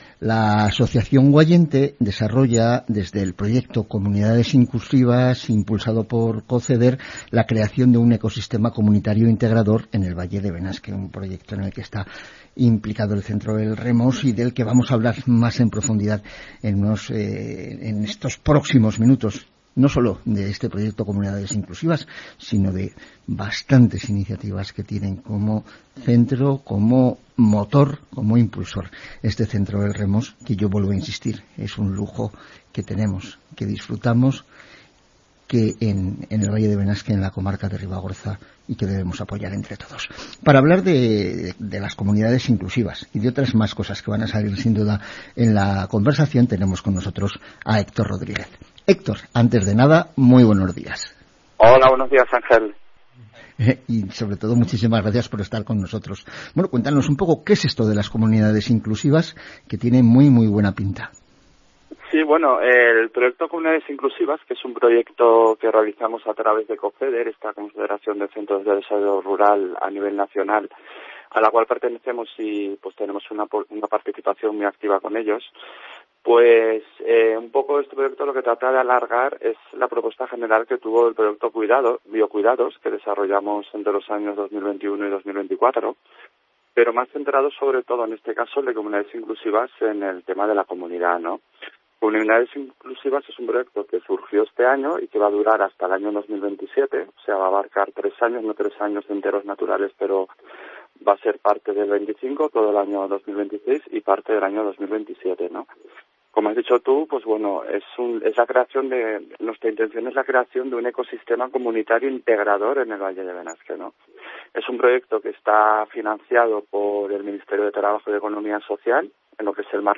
Antena del Pirineo, Asociación Guayente, 18 de noviembre de 2025 Escucha el audio Radio Ribagorza, COPE. Entrevista